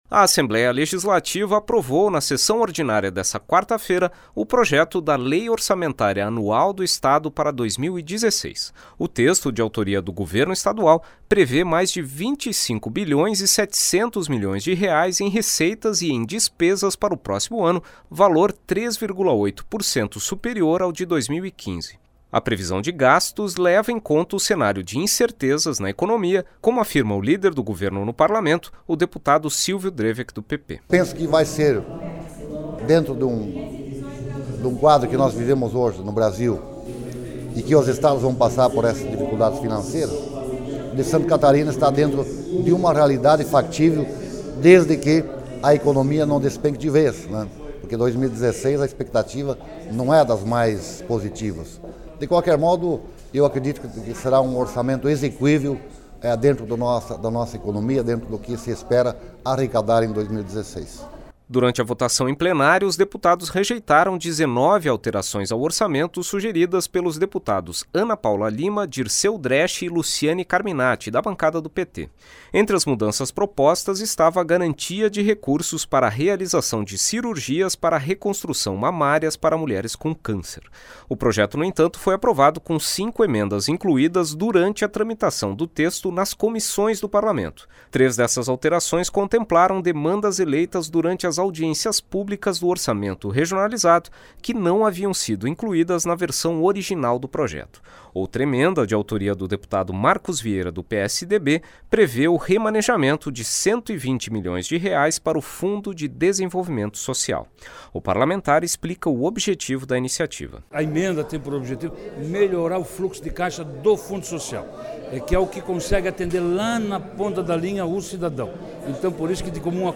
Entrevistas com: deputado Silvio Dreveck (PP), líder do governo na Assembleia Legislativa; e deputado Marcos Vieira (PSDB), relator da proposta.